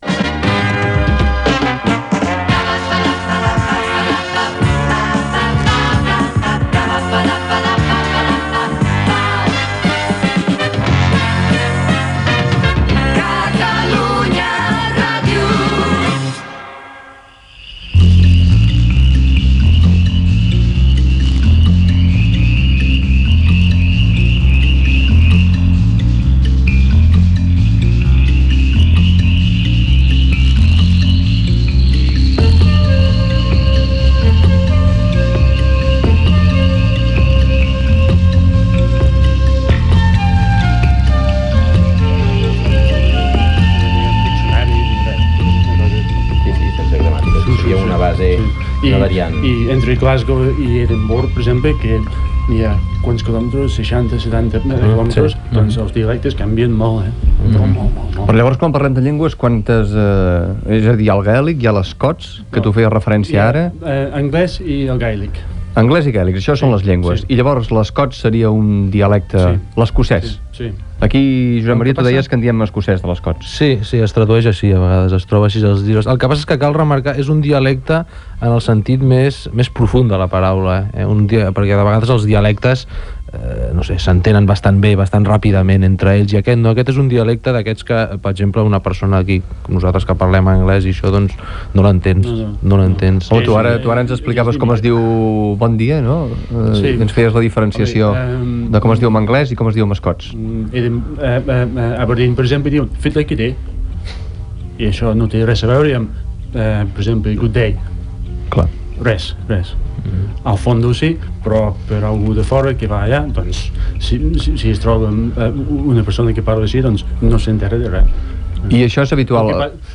Indicatiu de l'emissora, diàleg sobre el dialecte escocès, presentació dels invitats que participaran al programa dedicat a Escòcia, careta del programa, atractius del país
Divulgació